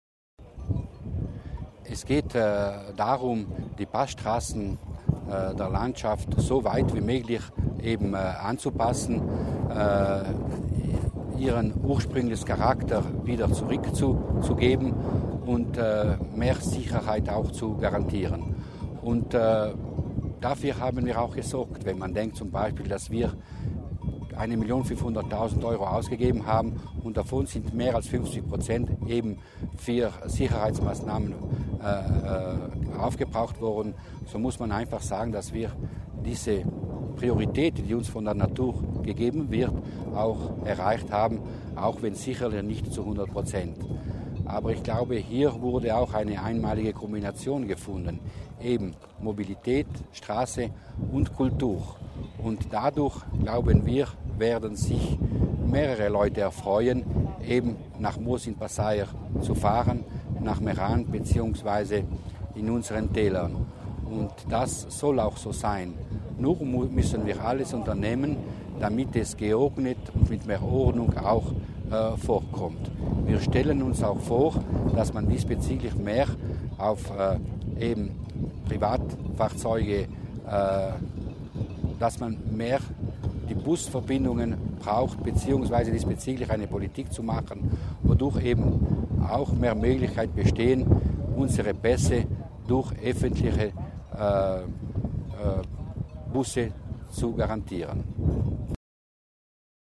Landesrat Mussner zur Bedeutung des Projektes